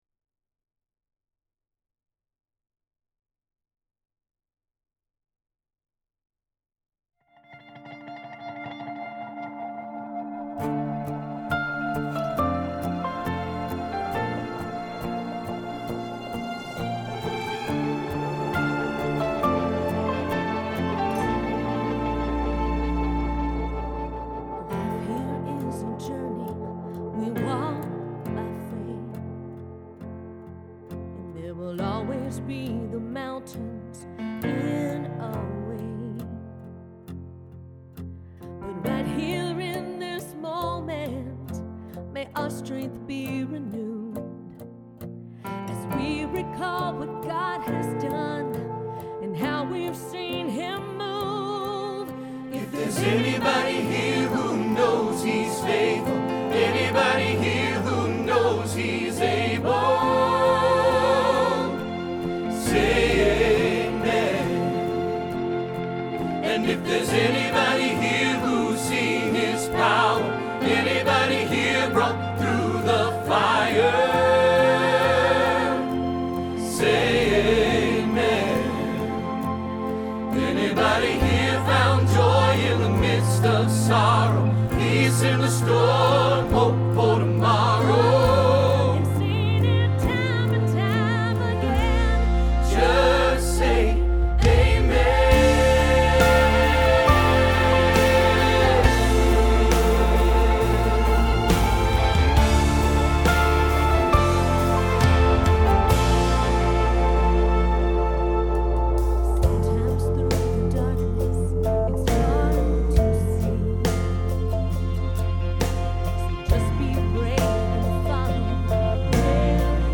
Say Amen – Tenor – Hilltop Choir
07-Say-Amen-tenor.mp3